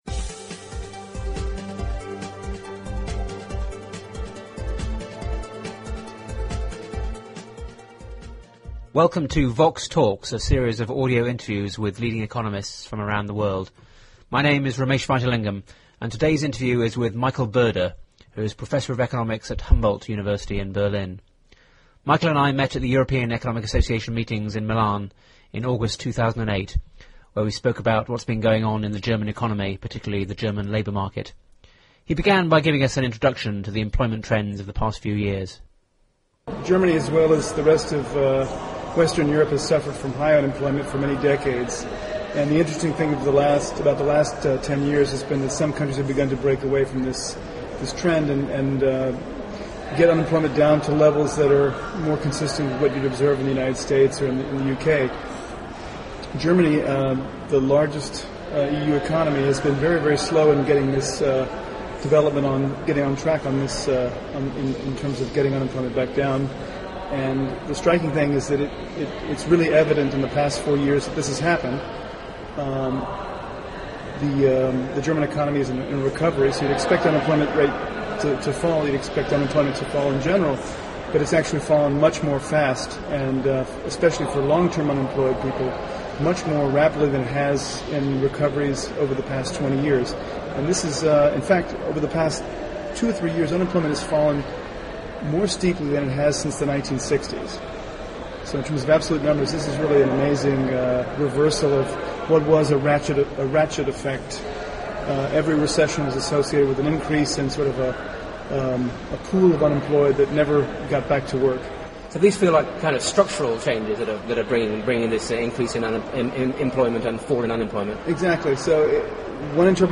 interviewed
12 September 2008 Supply side reforms have had a positive impact on the labour market in Germany, raising job creation and reducing the unemployment rate. At the European Economic Association meetings in Milan in August 2008